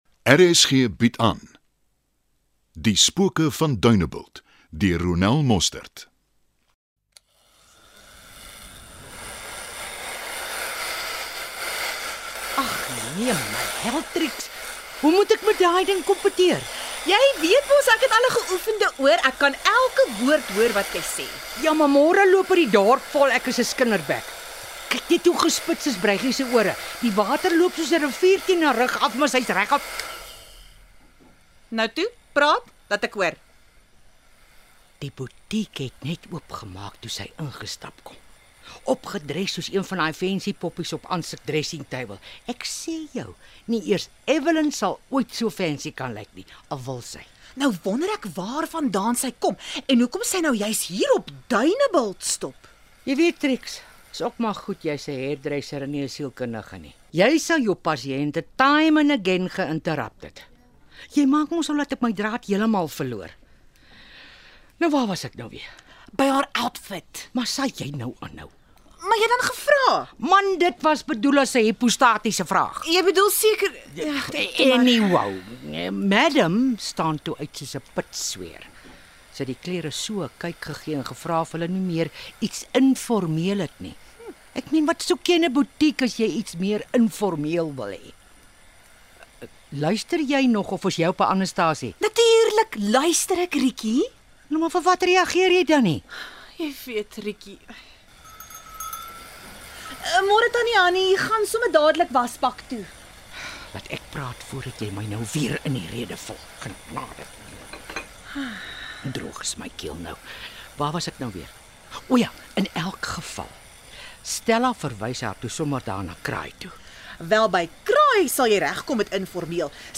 Die nuwe middagvervolgverhaal, Die spoke van Duinebult, deur Ronel Mostert, begin op 15 September.